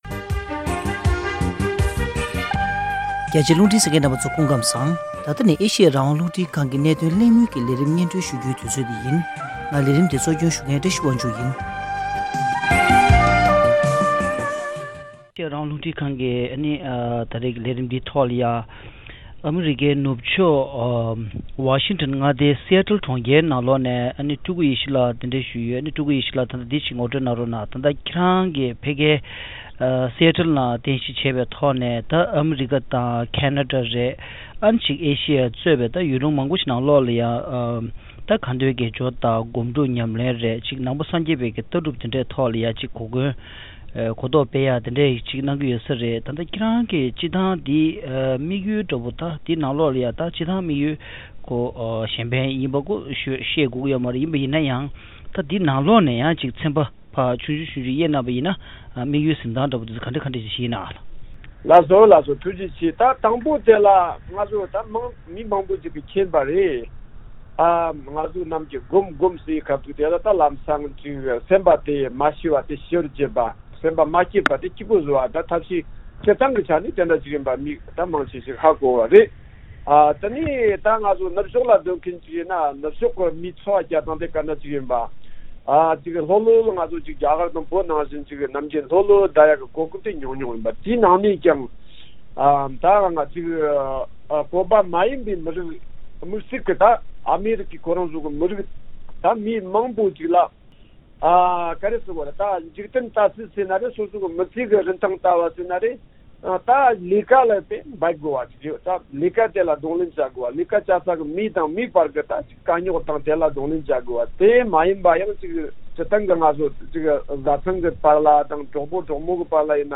བཅར་འདྲི་གླེང་མོལ